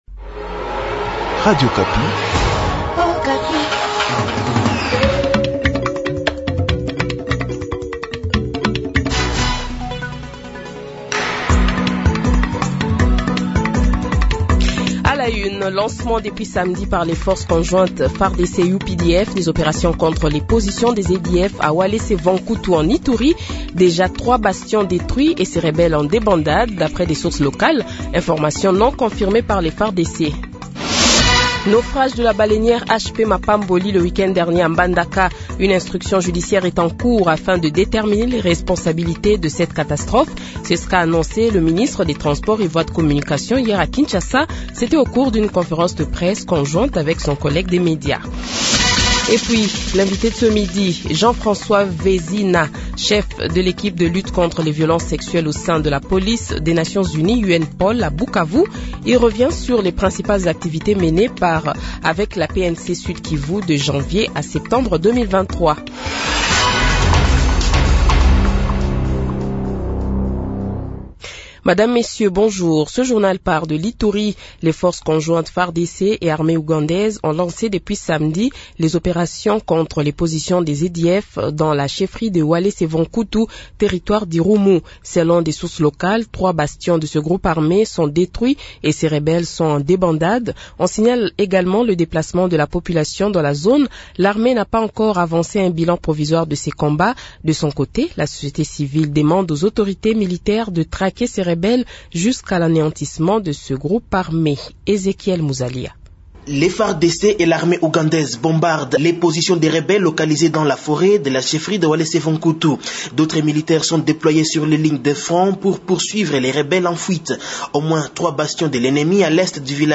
Journal Francais Midi